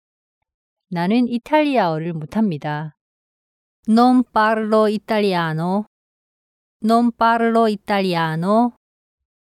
ㅣ논 빠를로 이딸리아아노ㅣ